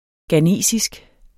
ghanesisk adjektiv Bøjning -, -e Udtale [ gaˈneˀsisg ] Betydninger fra Ghana; vedr.